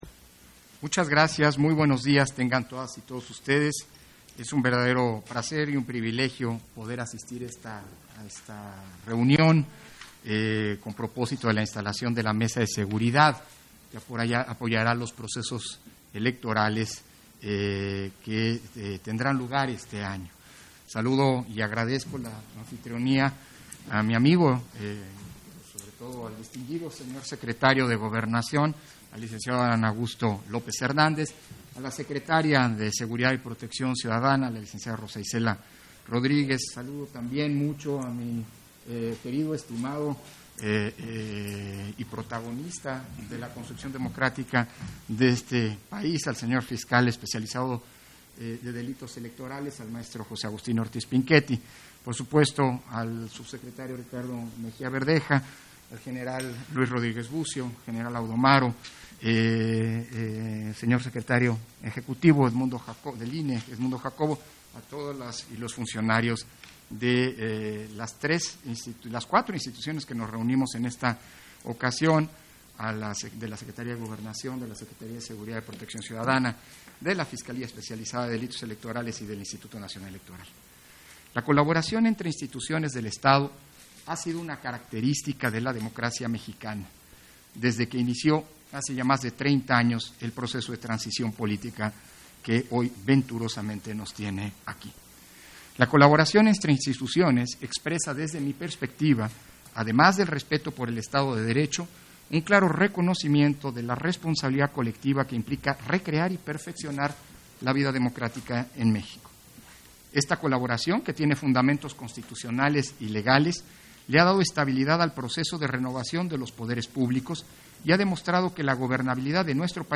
Intervención de Lorenzo Córdova, en la instalación de la Mesa de Estrategia de Protección y Seguridad en el contexto electoral 2022